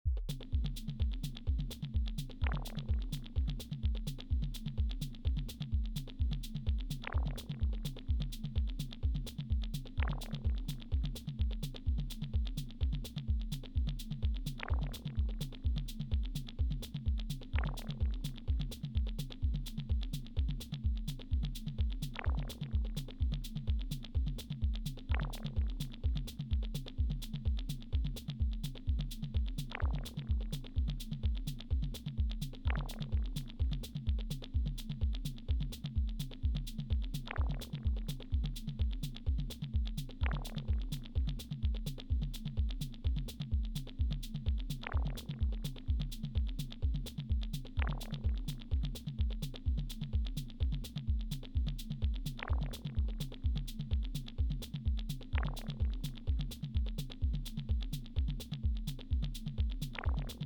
And here’s for CY : something colder, more “technoy”.